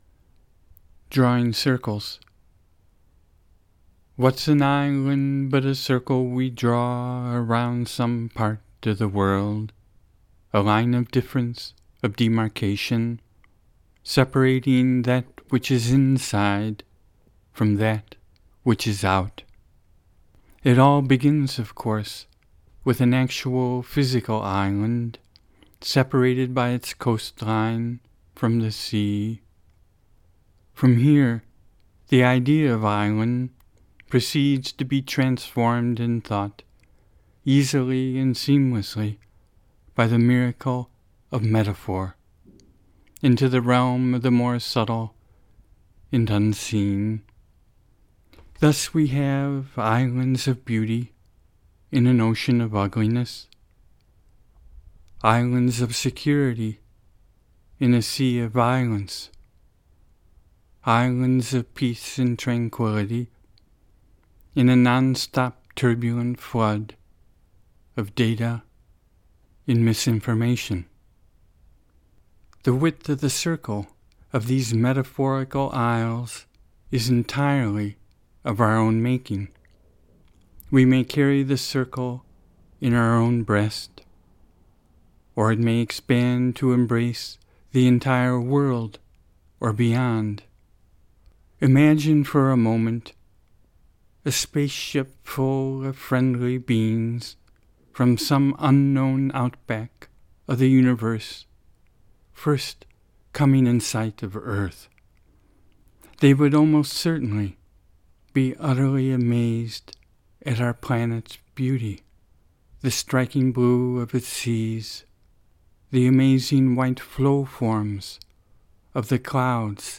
Eagle Cap Wilderness